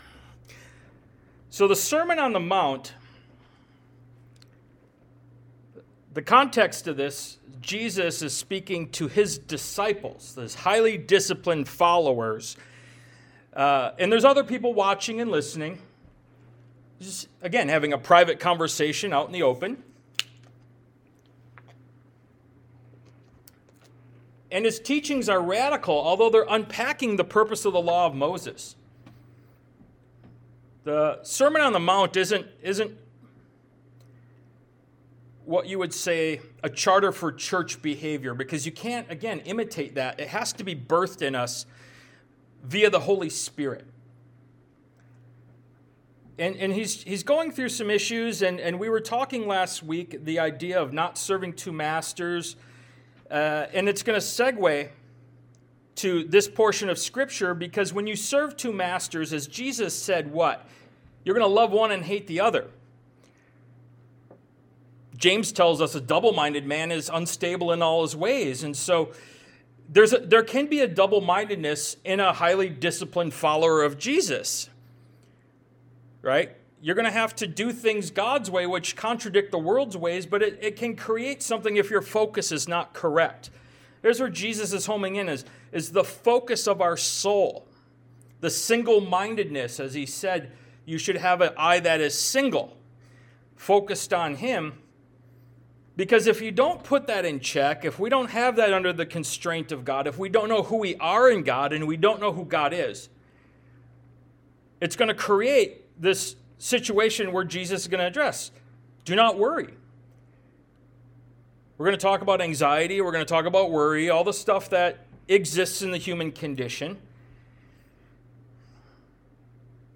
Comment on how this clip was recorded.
Ministry of Jesus Service Type: Sunday Morning « “Stay Focused” Ministry of Jesus Part 20 “Judge Righteously” Ministry of Jesus Part 22 »